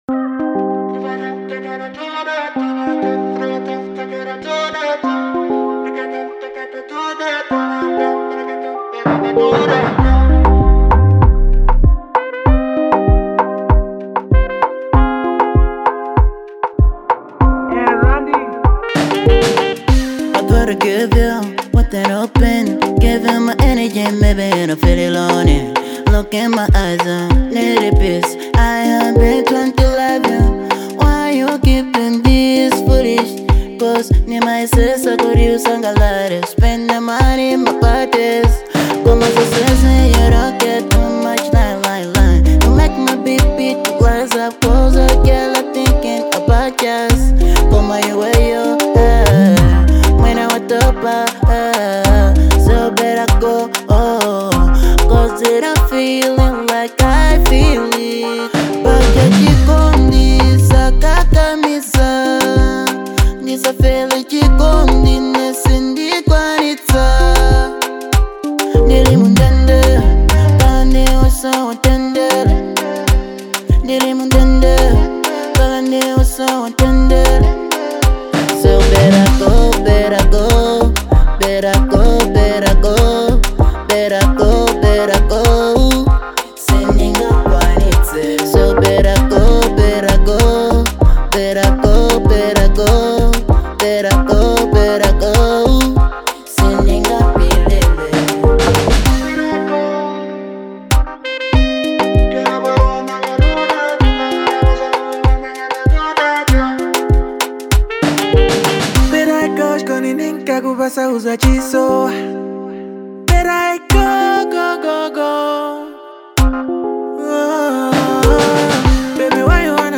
Genre : Afro-Pop